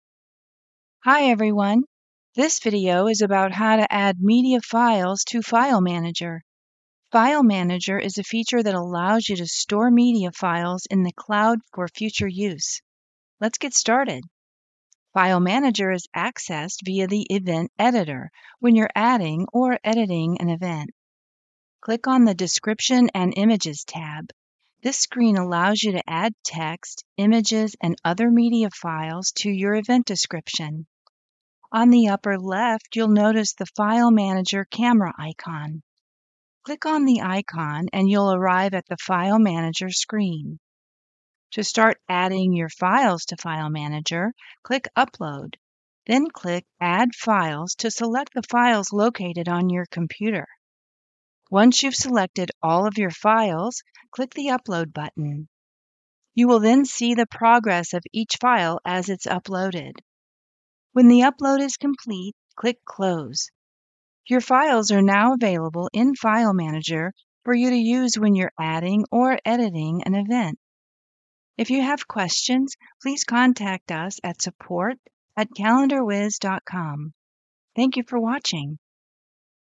Female
English (North American)
Yng Adult (18-29), Adult (30-50)
My voice is calm, confident and friendly. I have a low to mid range, which promotes easy listening. I emphasize important words that convey the client's core message. My recordings have a pleasant variation of sound so as not to be monotone.
E-Learning
Software Tutorial